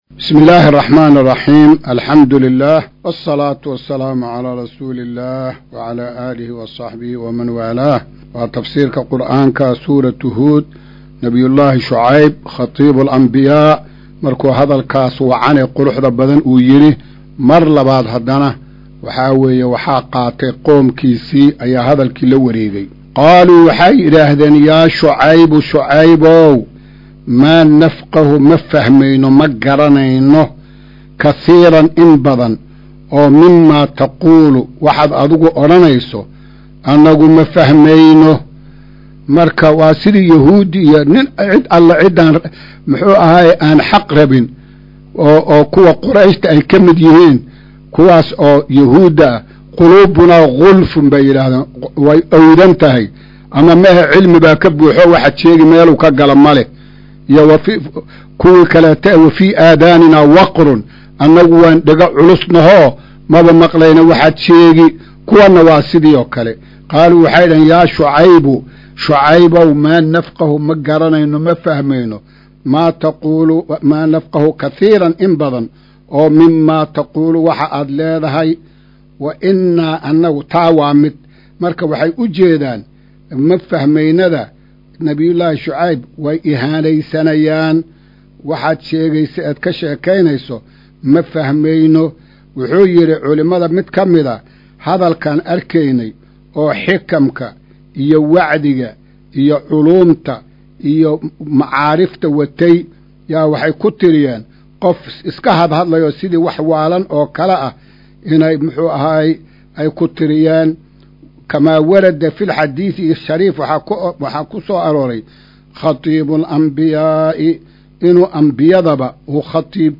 Maqal:- Casharka Tafsiirka Qur’aanka Idaacadda Himilo “Darsiga 115aad”